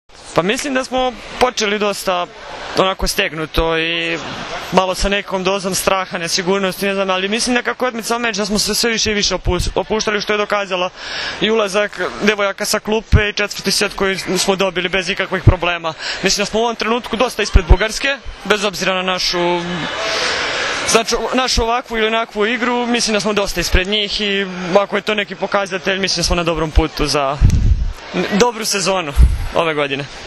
IZJAVA NATAŠE KRSMANOVIĆ